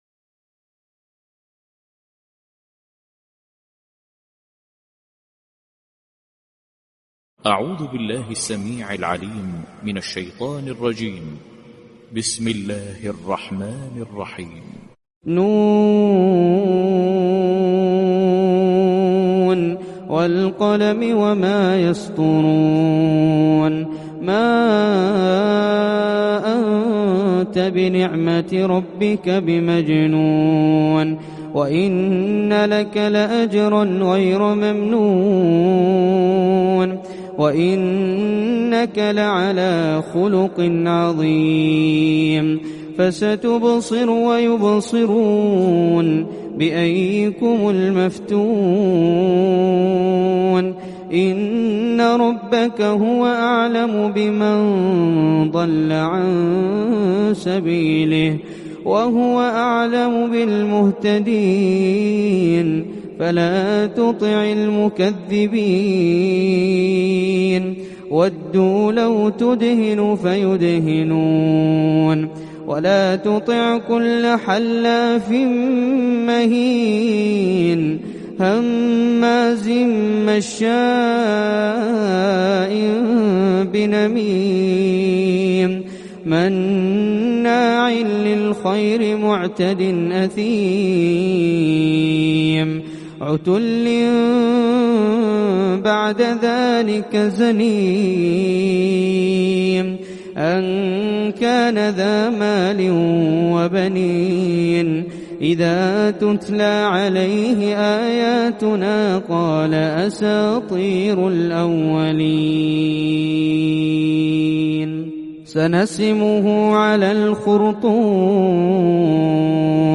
Surat Al Qalam Reciter Hazza Alblushi